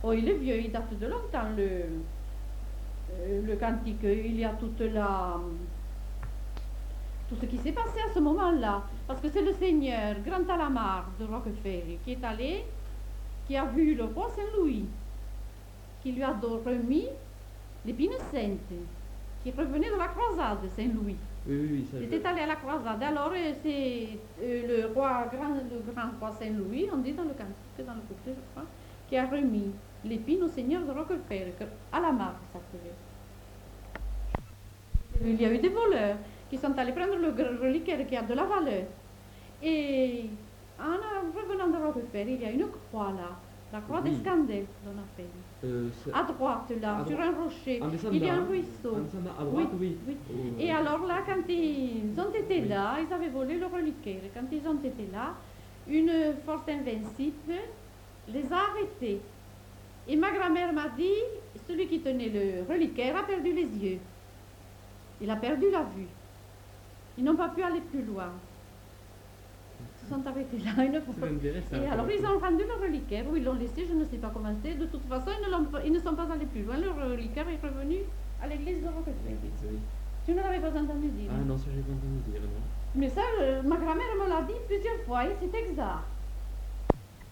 Lieu : Mas-Cabardès
Genre : conte-légende-récit
Type de voix : voix de femme
Production du son : parlé
Classification : récit légendaire